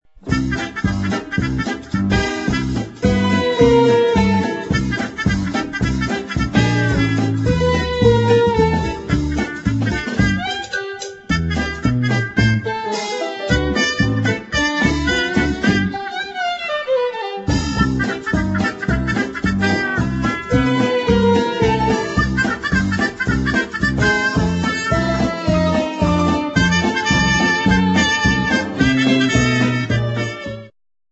funny fast instr.